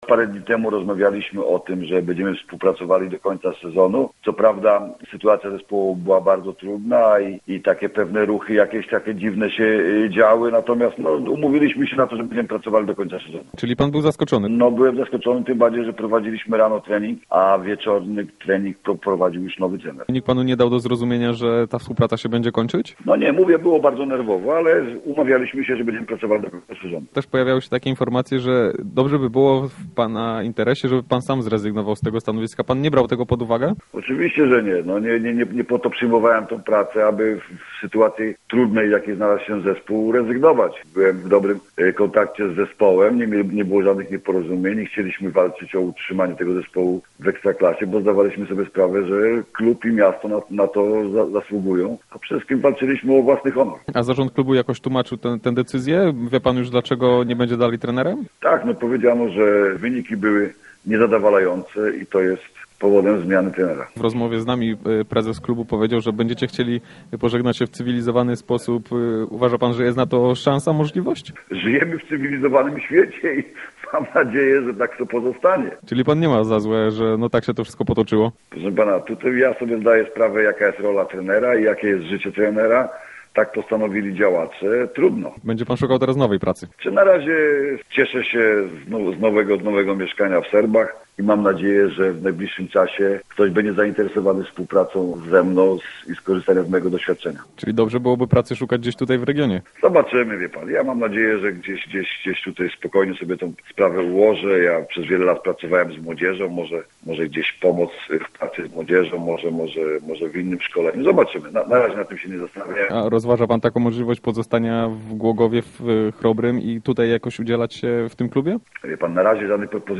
Poniżej rozmowa